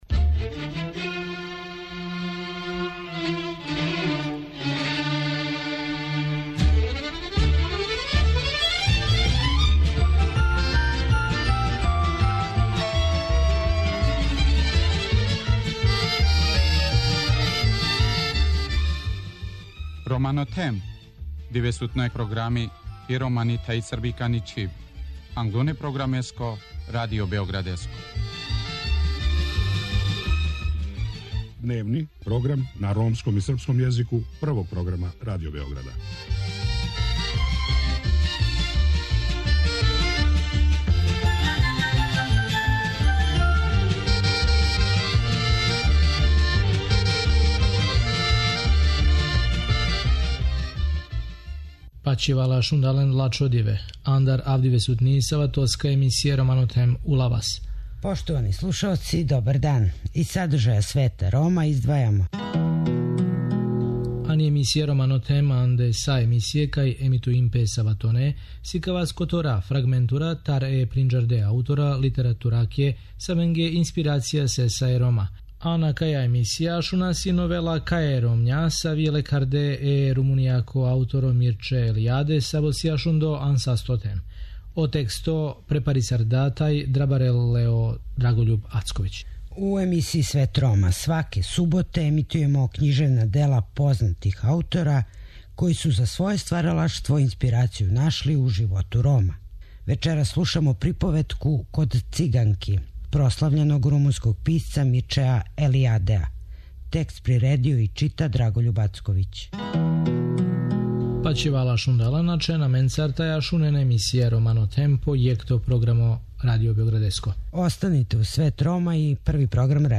Вечерас слушамо приповетку "Код Циганки" прослављеног румунског писца Мирчеа Елијадеа.